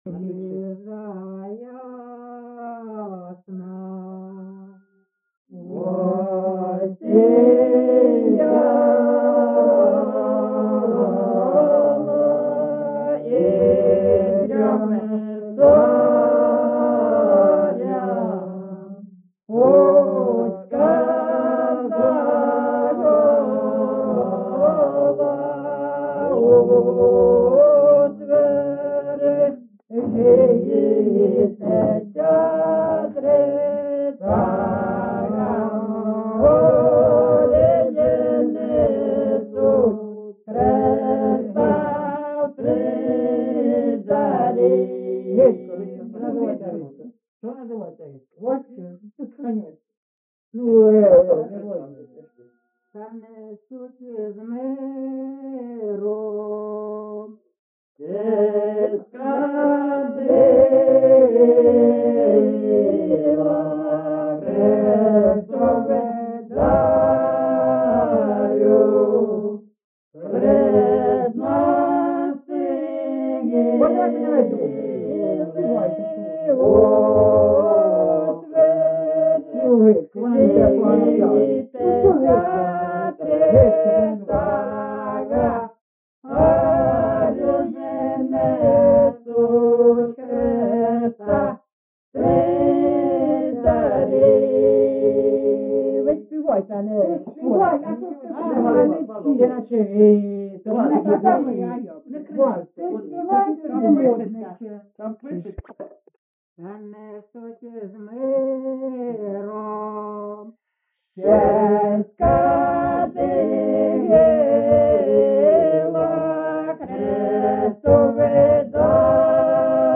GenreChristmas Carol (Kolyadka)
Recording locationLyman, Zmiivskyi (Chuhuivskyi) District, Kharkiv obl., Ukraine, Sloboda Ukraine